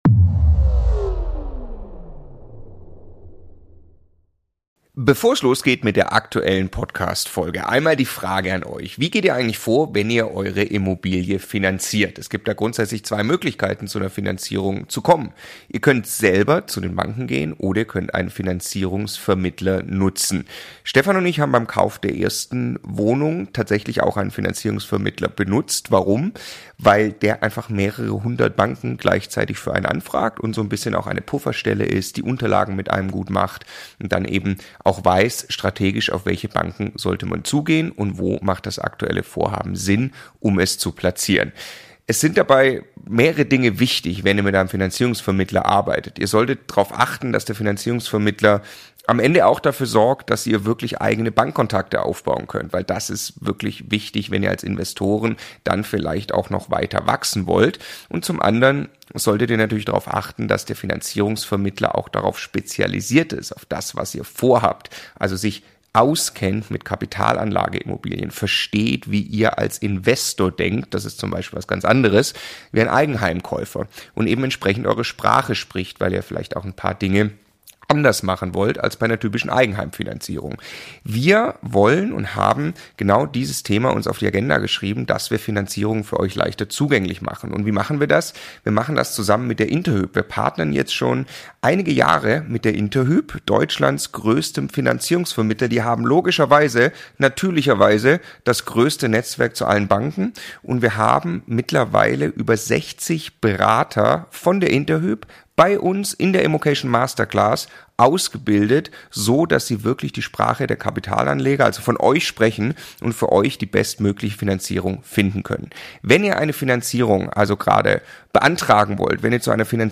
593 | So haben wir 180 Wohnungen gemeinsam gekauft (Interview